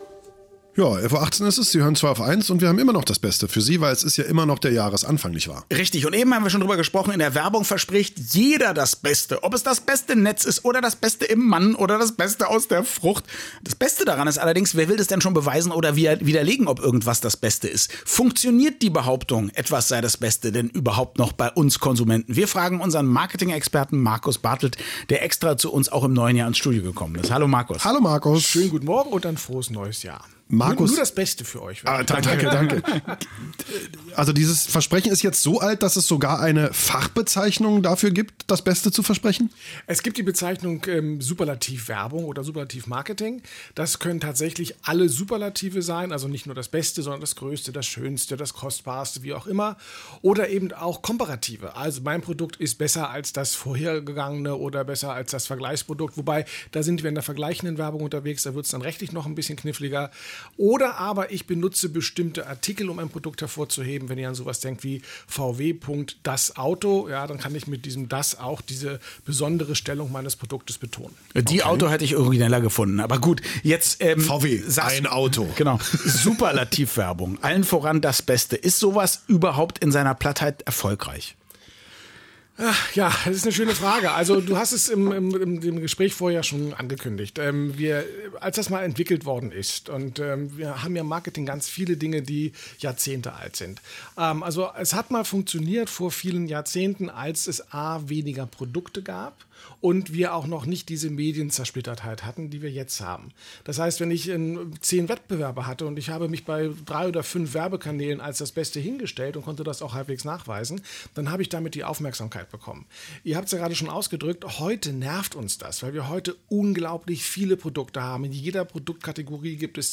und so war auch ich wieder einmal zu Gast bei „Zweiaufeins“ im radioeins-Studio, um über Superlative in der Werbung zu sprechen: